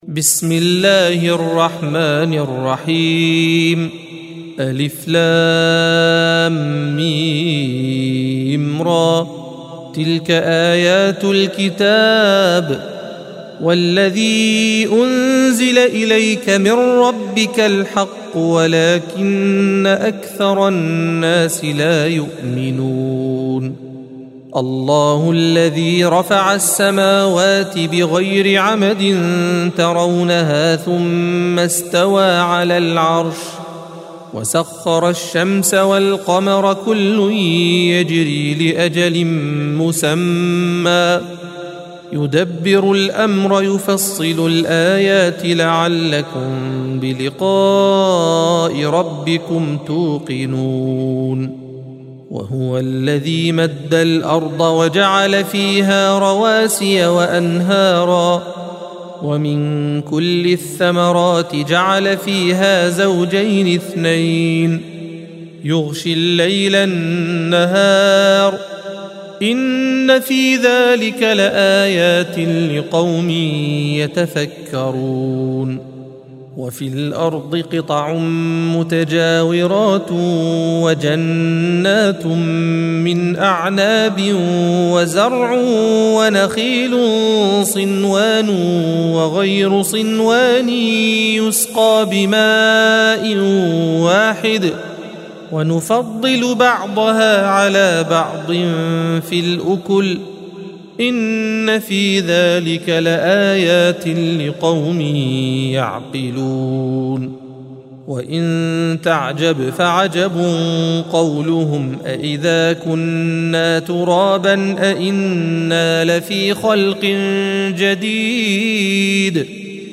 الصفحة 249 - القارئ